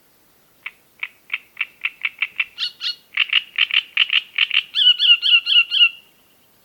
Art: Trostesanger (Acrocephalus arundinaceus)
Sang